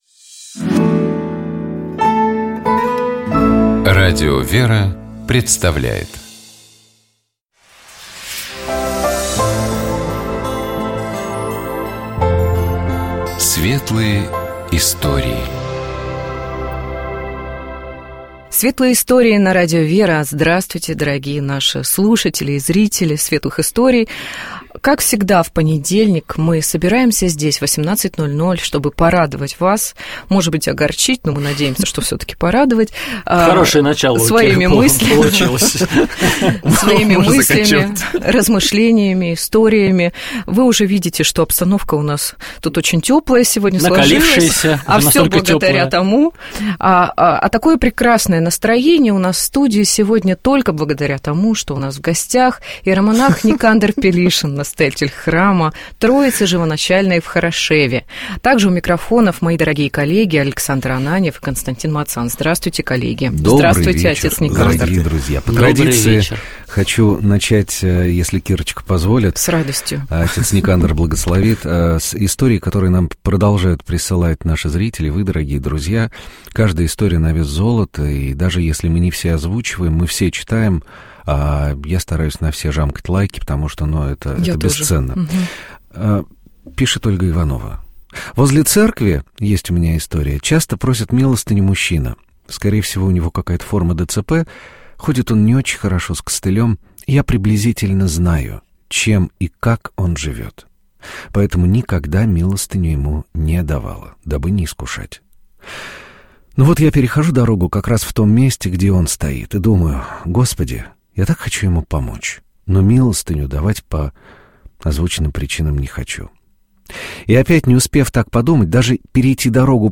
«Светлые истории» — это цикл программ, в которых ведущие Радио ВЕРА и гости в студии делятся историями из своей жизни. Историями о сомнениях, о радости, о вере, о любви…